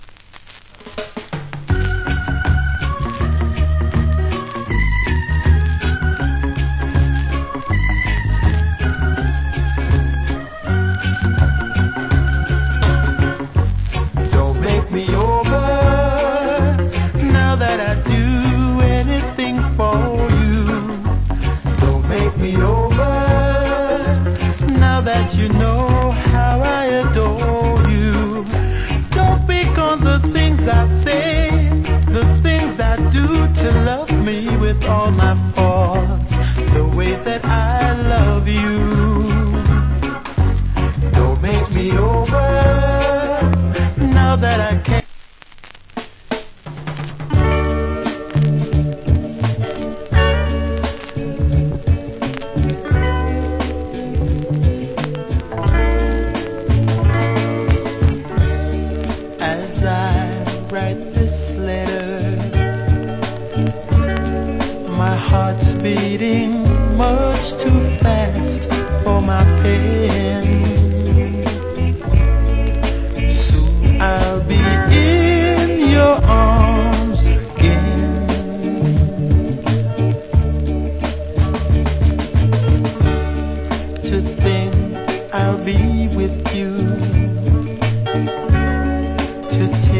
Nice Early Reggae.